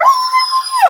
Soundscape Overhaul / gamedata / sounds / monsters / dog / bdog_hurt_0.ogg
bdog_hurt_0.ogg